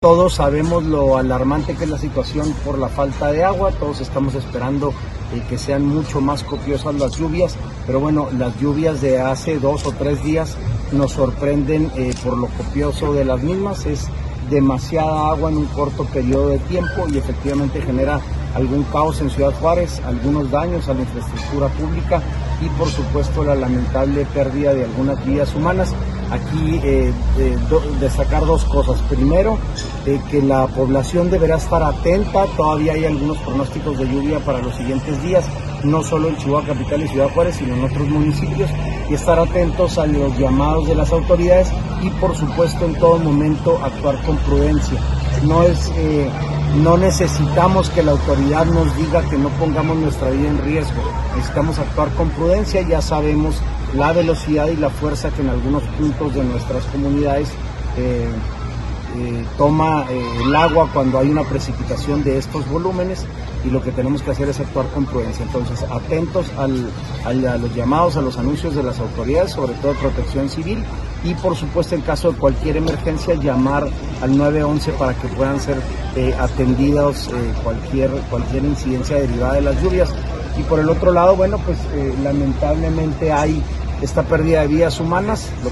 AUDIO: SANTIAGO DE LA PEÑA, SECRETARIO GENERAL DE GOBIERNO (SGG)